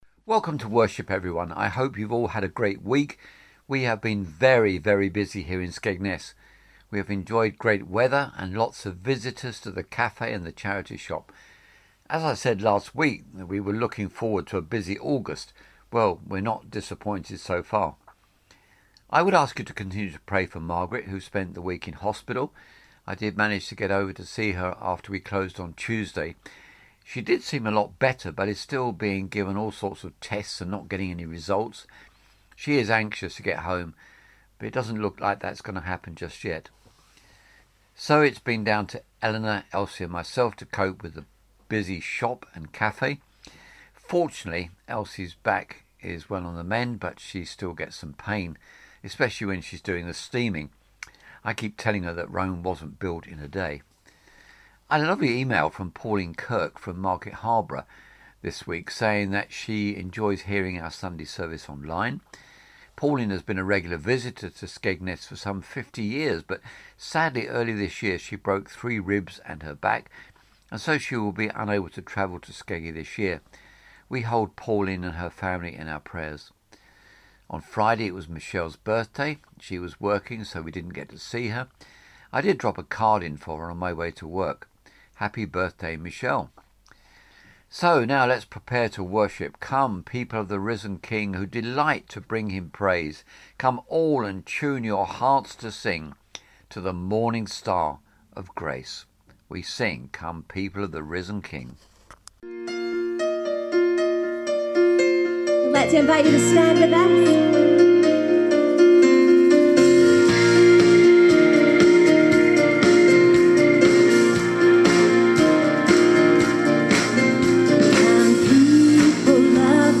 Online Service for Sunday 18th August 2024